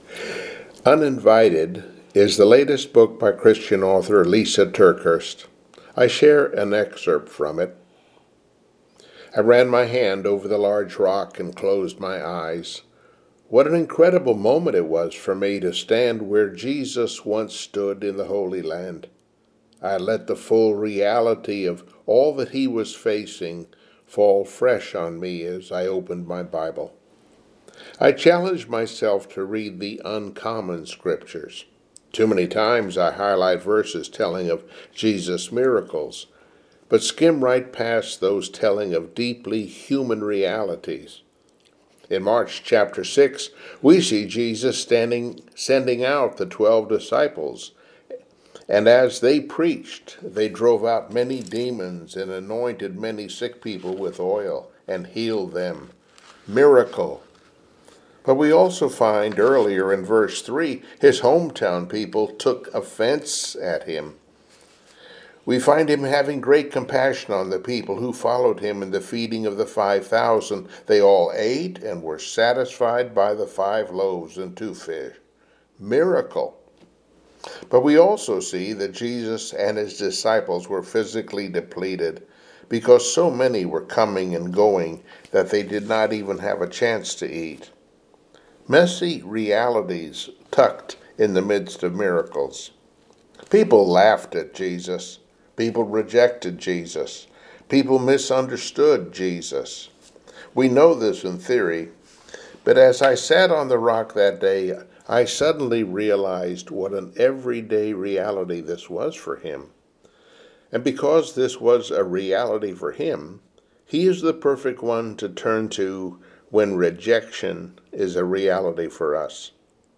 Joshua 3 Service Type: Sunday Morning Worship Mark 6:52 for they had not understood about the loaves